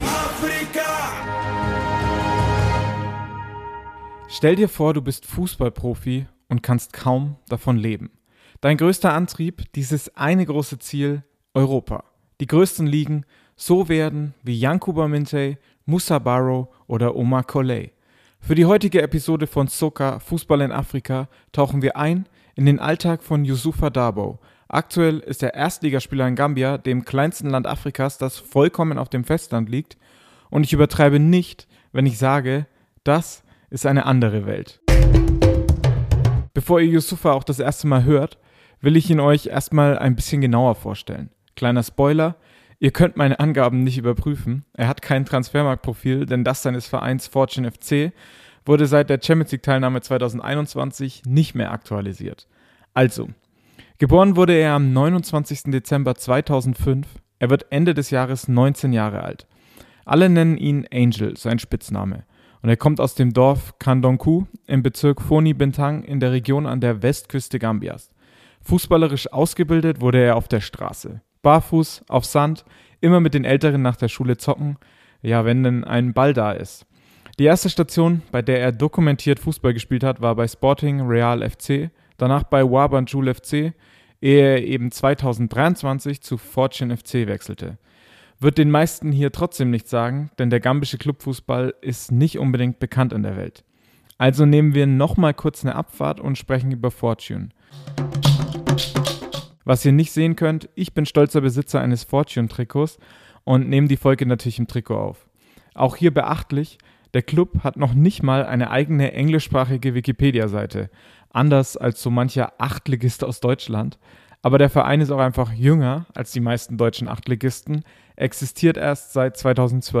Ein Bericht über die harte Realität im Land an der Westküste Afrikas.